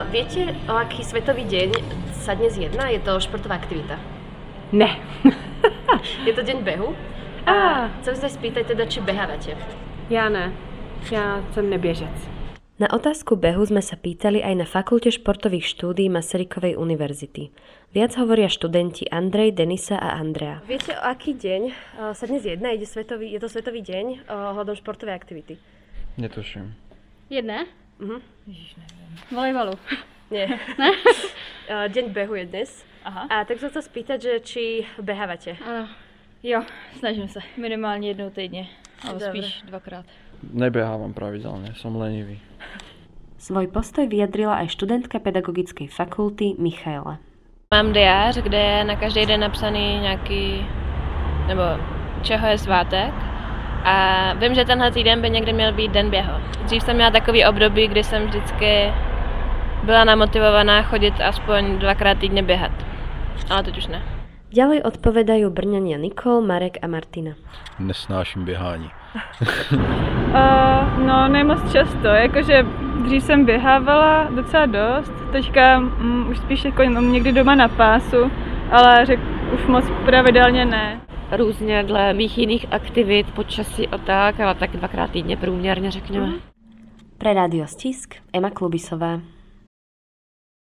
Anketa: Ako sú na tom s behom Brňania?
Brno – Dnes je svetový deň behu, ktorý nadšenci tejto športovej aktivity oslavujú od roku 1999. V brnenských uliciach sme sa pýtali ľudí či tento deň poznajú a či sami behávajú.